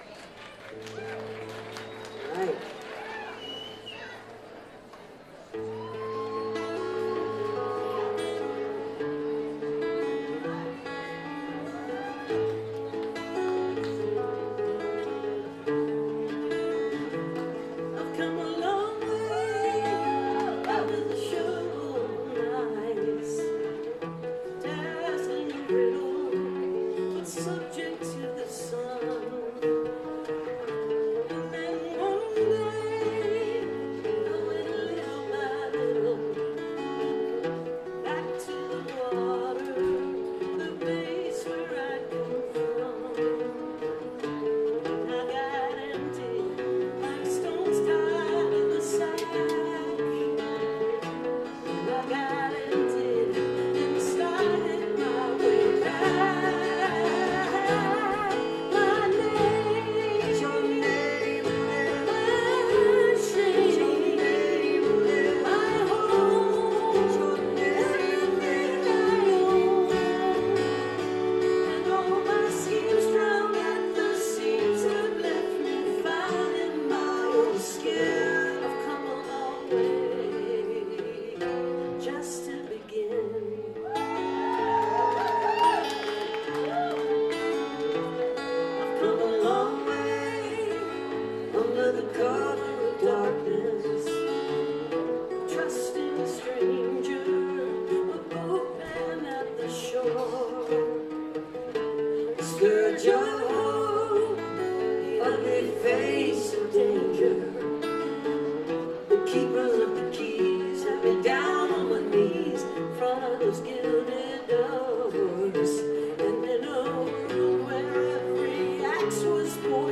live stream on twitch